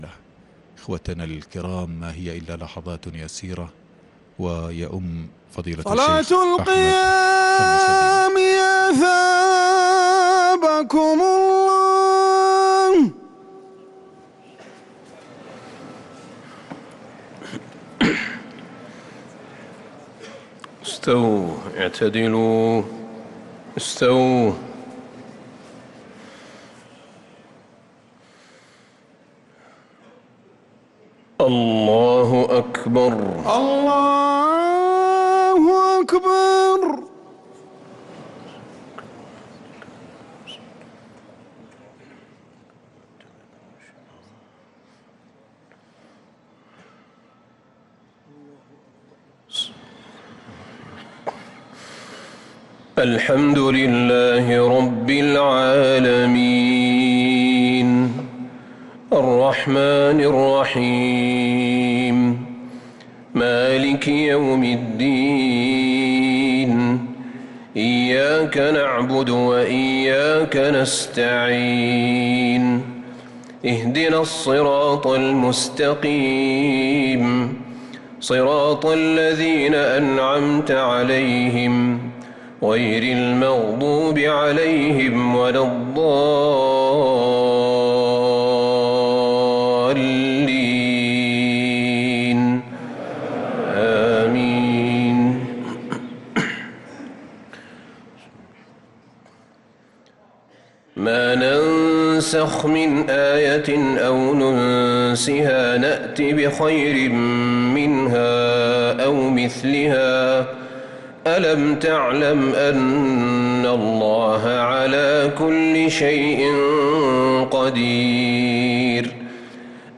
تراويح ليلة 2 رمضان 1445هـ من سورة البقرة {106-167} Taraweeh 2st night Ramadan 1445H > تراويح الحرم النبوي عام 1445 🕌 > التراويح - تلاوات الحرمين